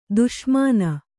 ♪ duṣmāna